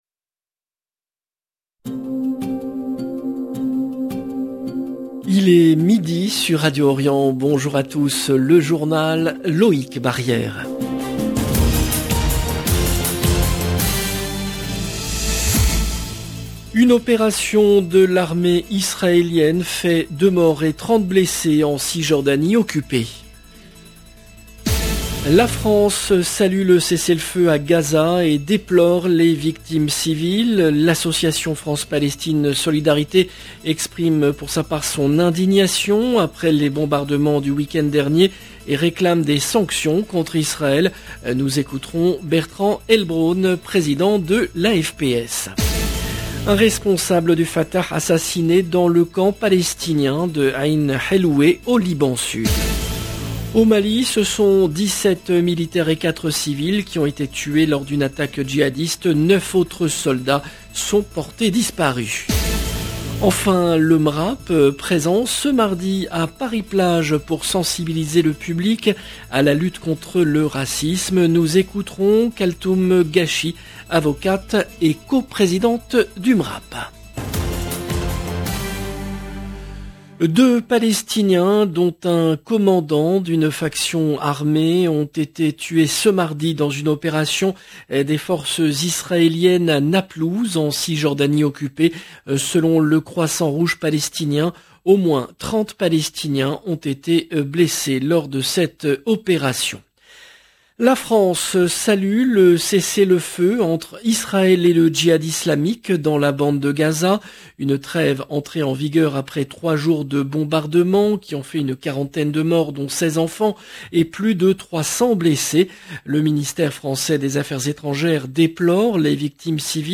LE JOURNAL EN LANGUE FRANCAISE DE MIDI DU 9/08/22